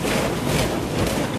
techage_quarry.ogg